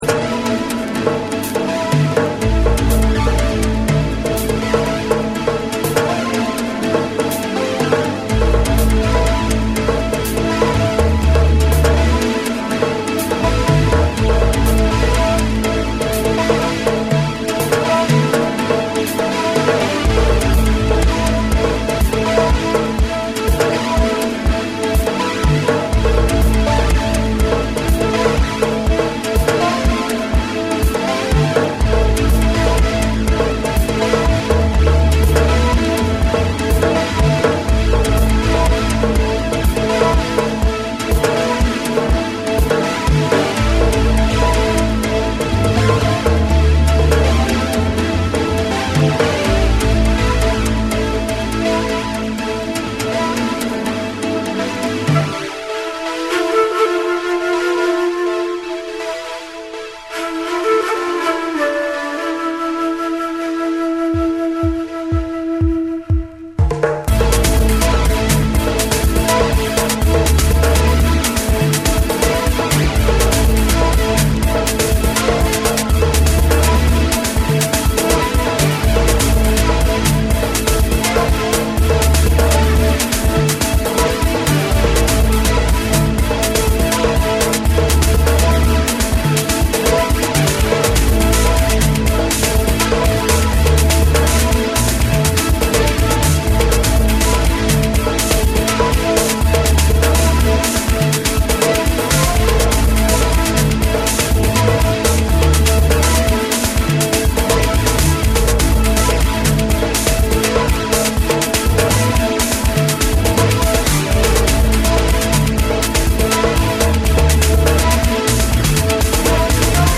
パーカッシブなリズムと深みのあるベースライン、そしてオリエンタルなメロディが交差する、ディープかつオーガニックな
TECHNO & HOUSE / ORGANIC GROOVE